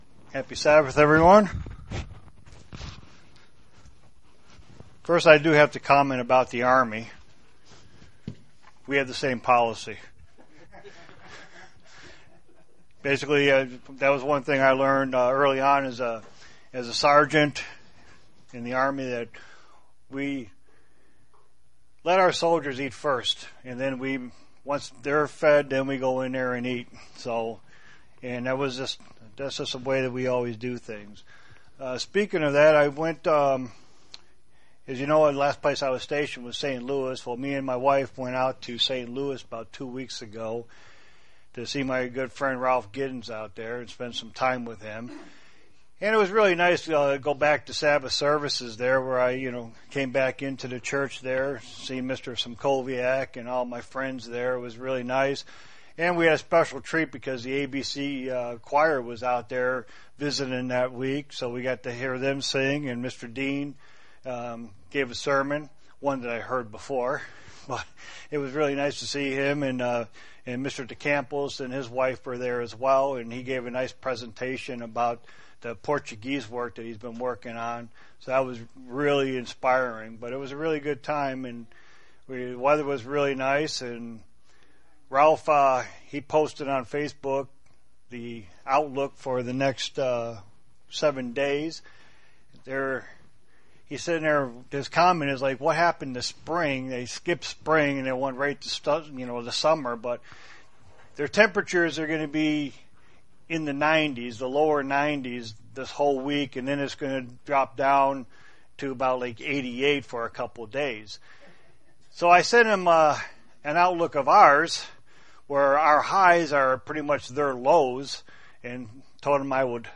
Sermons
Given in Elmira, NY